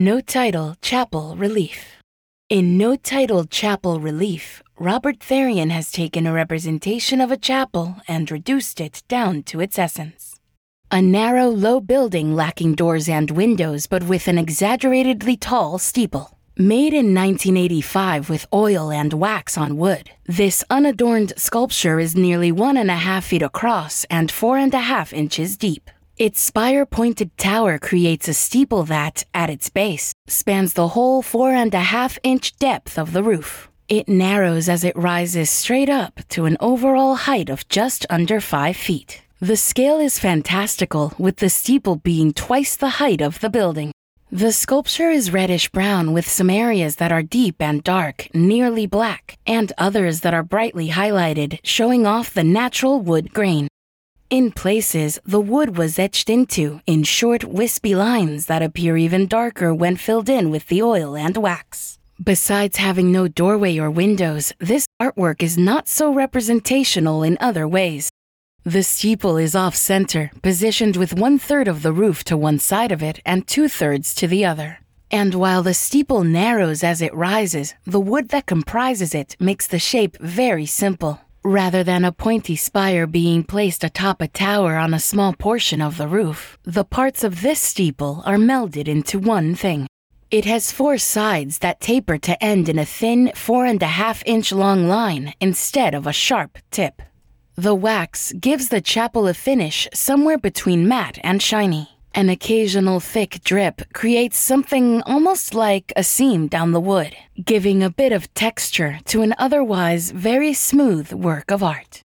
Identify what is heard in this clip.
Audio Description (01:52)